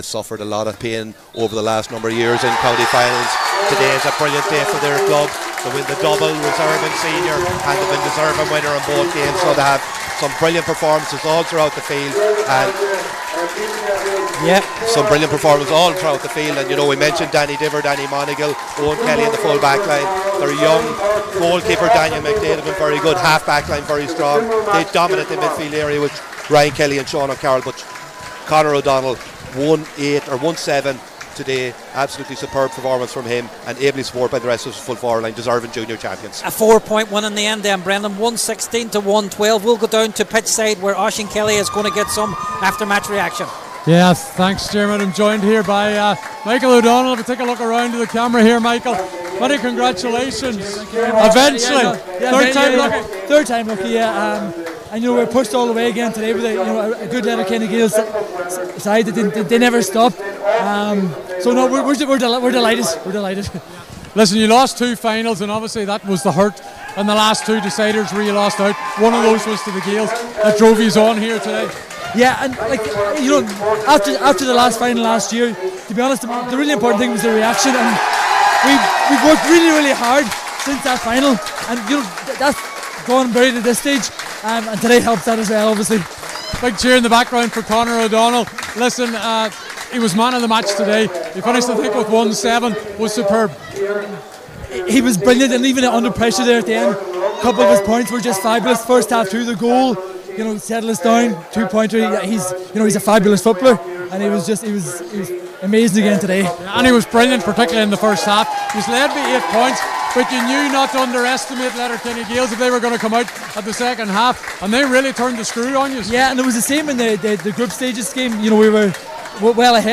live at full time